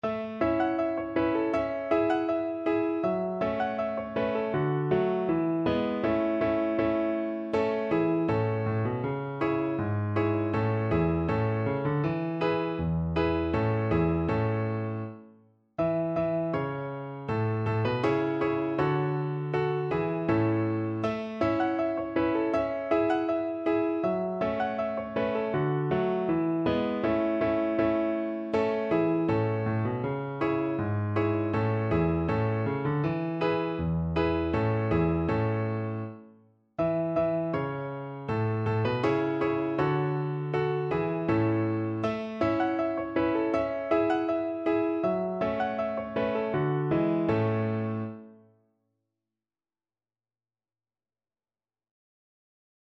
Play (or use space bar on your keyboard) Pause Music Playalong - Piano Accompaniment Playalong Band Accompaniment not yet available reset tempo print settings full screen
2/4 (View more 2/4 Music)
A major (Sounding Pitch) (View more A major Music for Violin )
Steadily =c.80
Traditional (View more Traditional Violin Music)